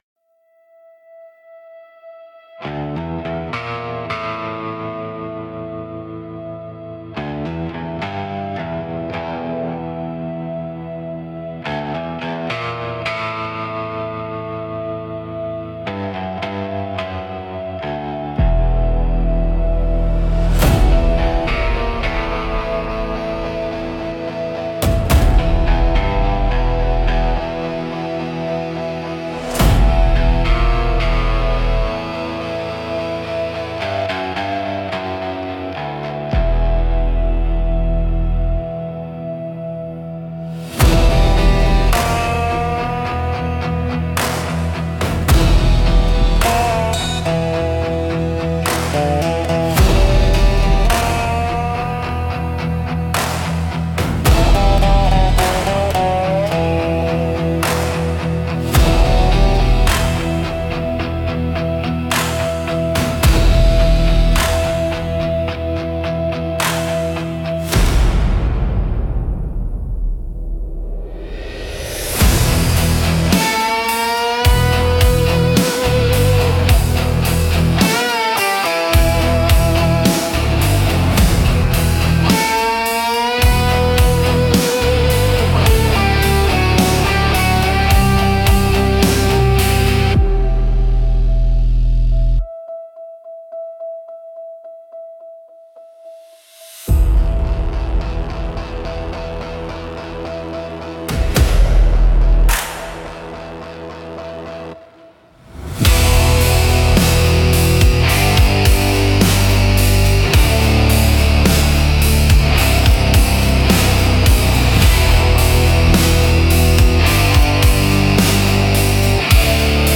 Modern Western Pulse